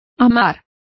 Also find out how amado is pronounced correctly.